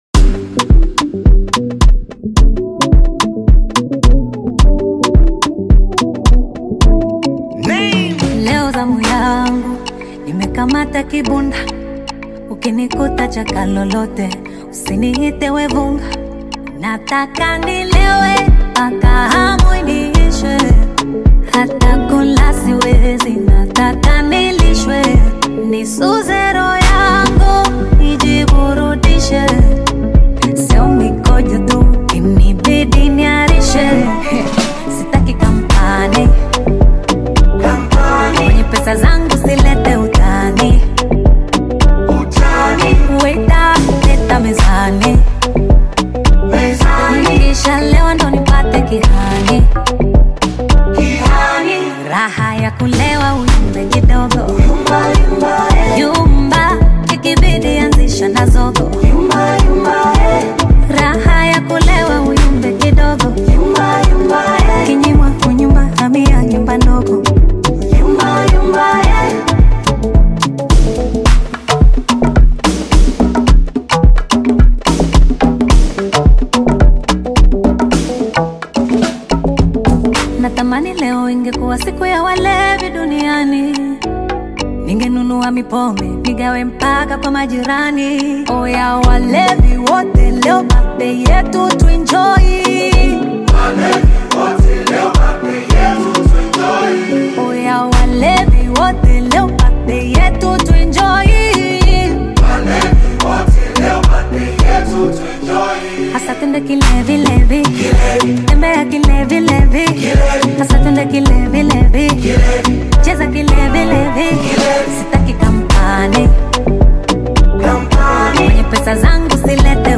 Tanzanian Bongo Fleva star and 2018 Bongo Star Search winner